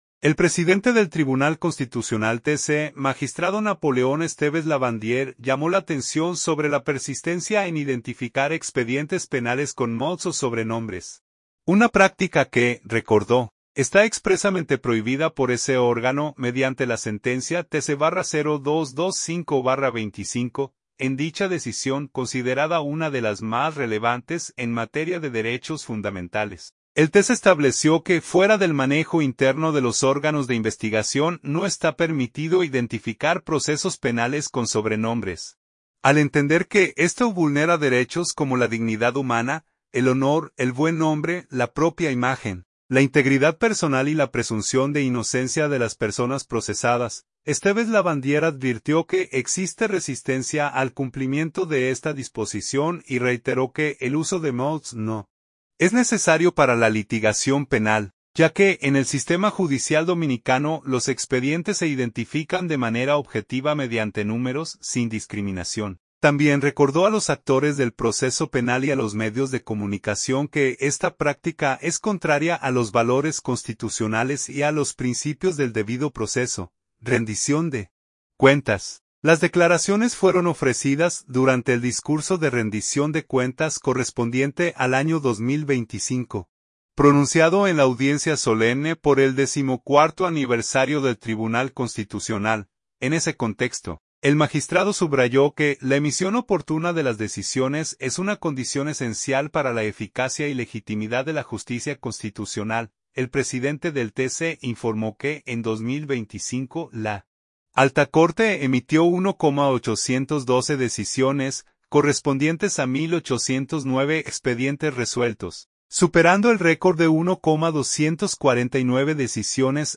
Las declaraciones fueron ofrecidas durante el discurso de rendición de cuentas correspondiente al año 2025, pronunciado en la audiencia solemne por el décimo cuarto aniversario del Tribunal Constitucional